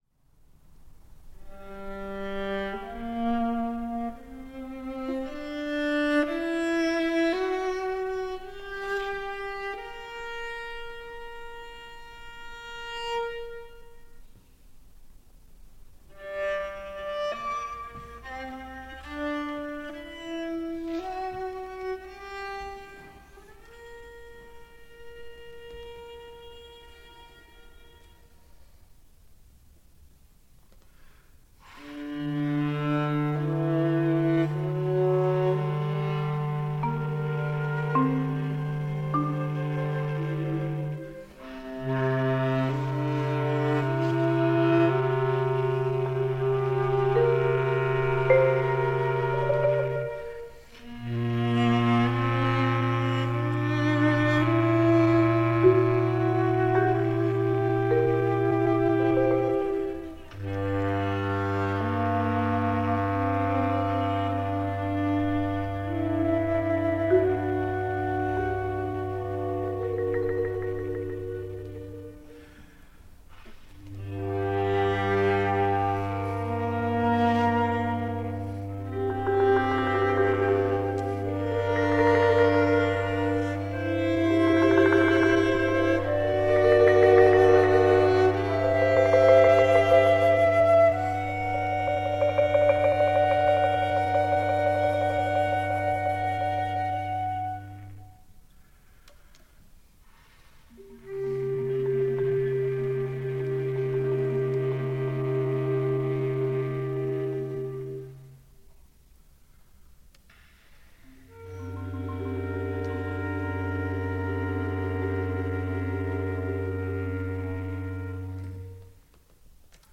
Flute
Percussion
Bass Clarinet
Viola